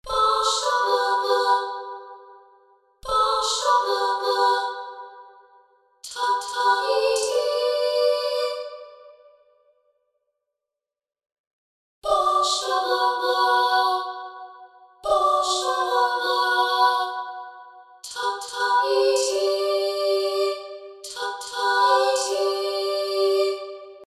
Schließlich animiere ich die zusätzlichen Stimmen über Lautstärkekurven.
Das Intro – ohne Instrumente – hört sich nun so an: